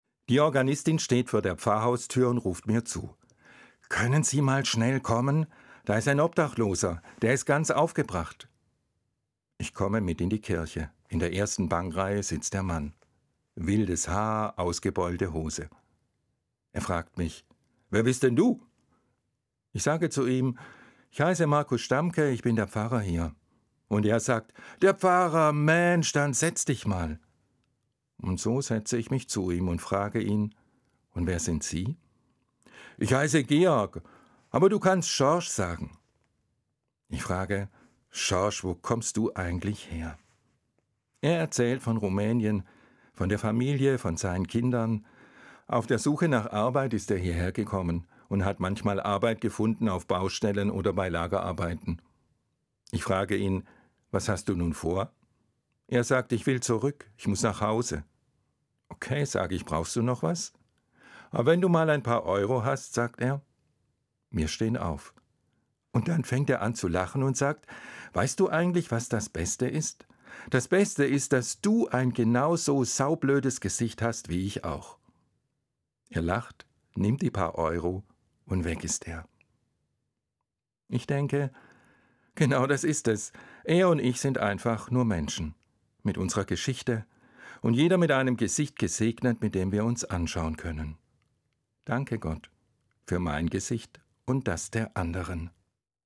Evangelischer Pfarrer, Limburg